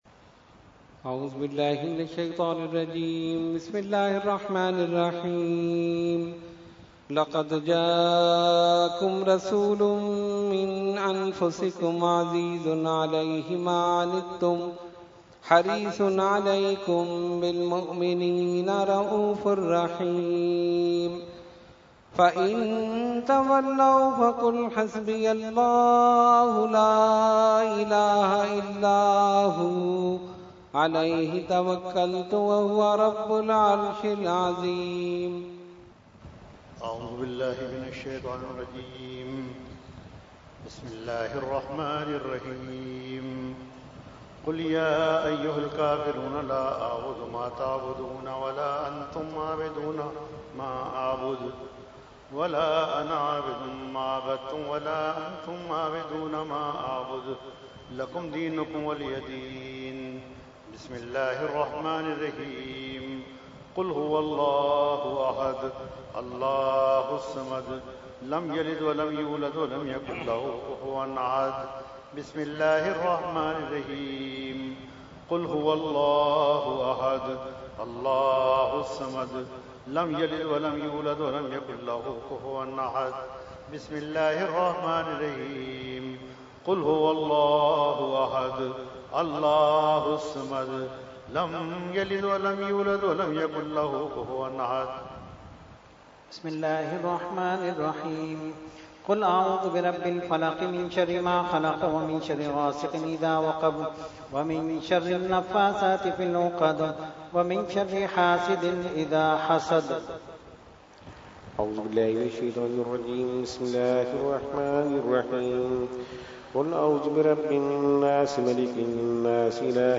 Category : Fatiha wa Dua
18-Fatiha & Dua.mp3